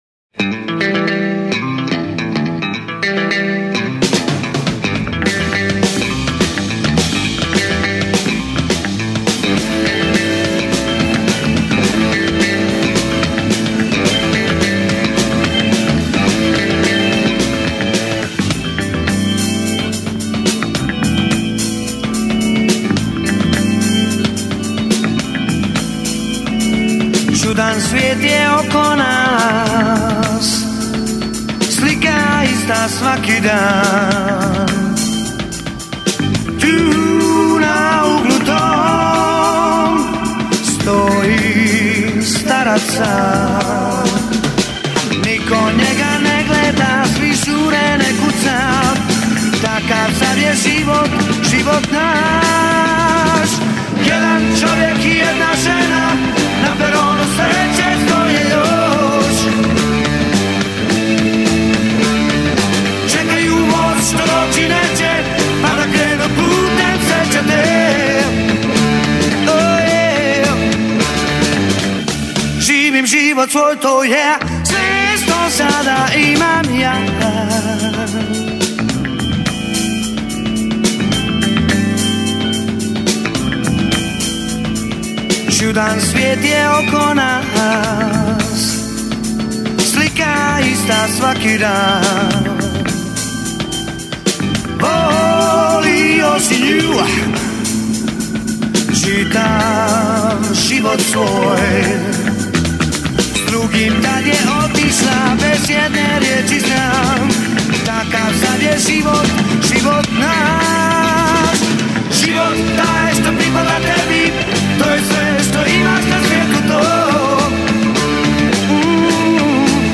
gitarista